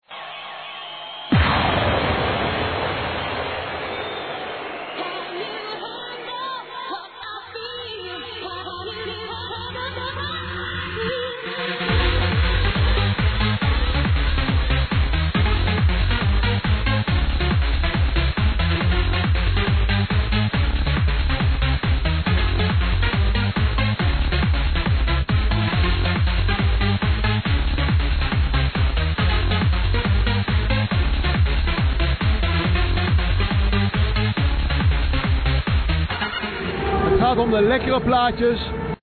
Need track IDs from the trance energy 2006 vid